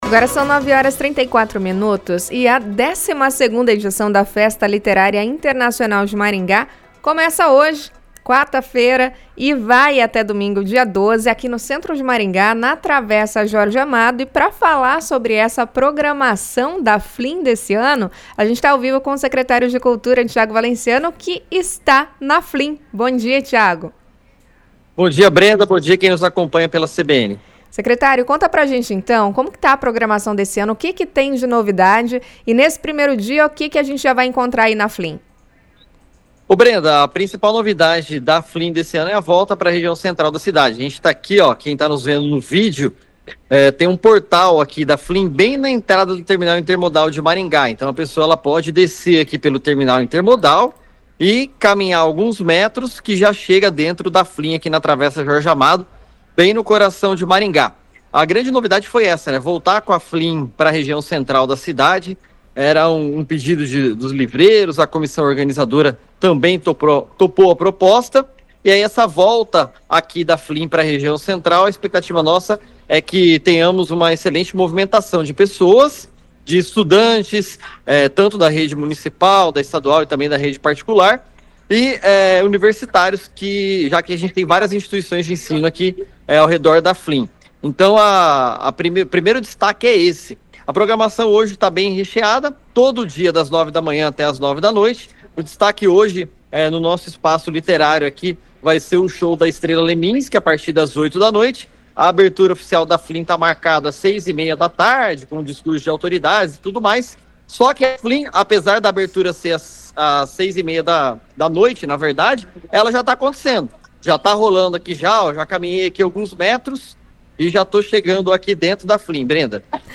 O secretário de Cultura, Tiago Valenciano, dá os detalhes sobre a edição 2025, destacando a volta ao centro e o apoio a publicações independentes.